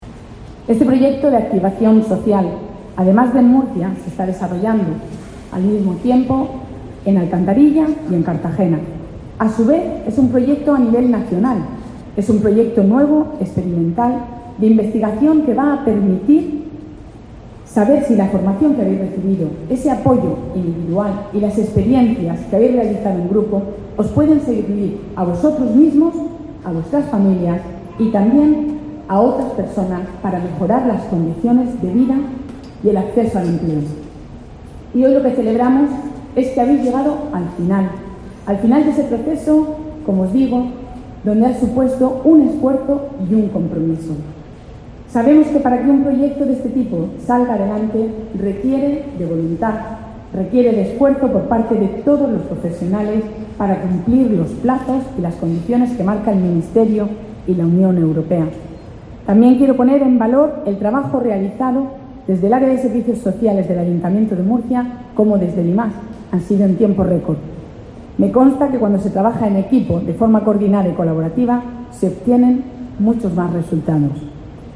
Pilar Torres, concejala de Bienestar Social, Familia